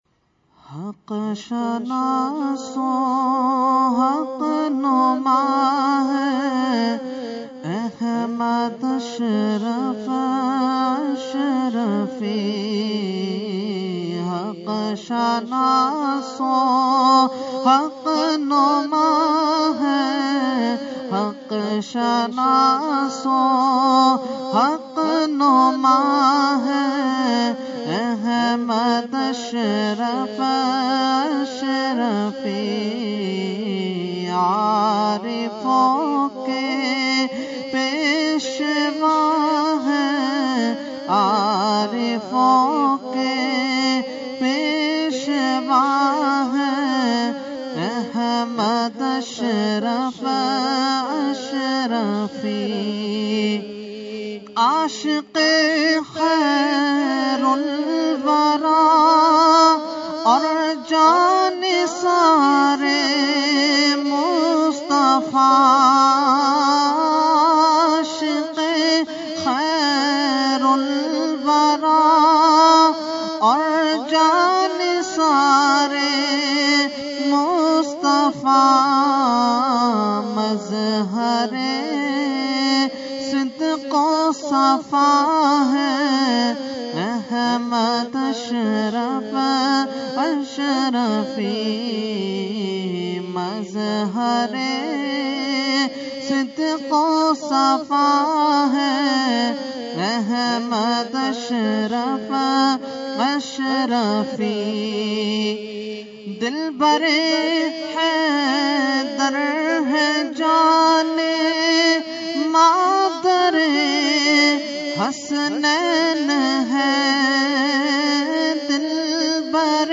Category : Manqabat | Language : UrduEvent : Urs Qutbe Rabbani 2016